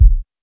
KICK.34.NEPT.wav